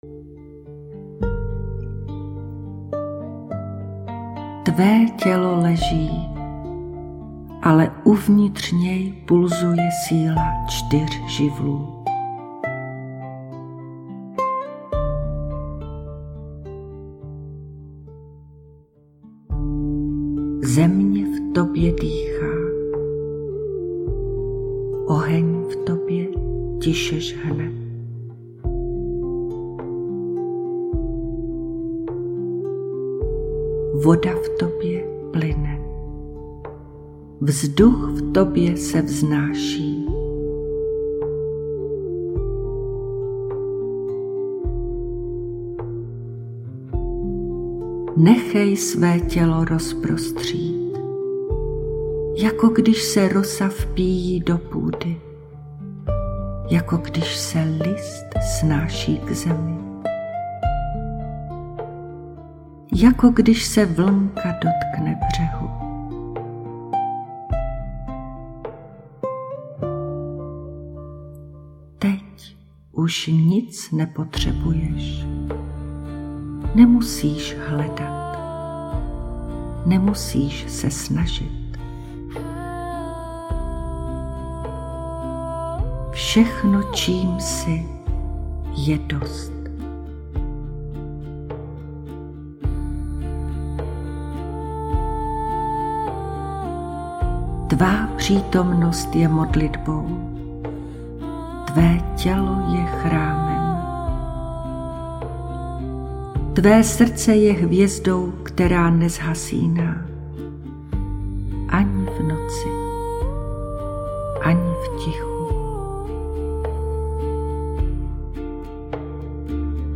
Meditace Návrat do ticha živlů – ukázka – celková délka 00:18:37
Meditace, která následuje, je vedená tak, aby ti dovolila:
Hudba v této fázi je jemná, ženská, nevtíravá – aby tě držela, ale nerušila.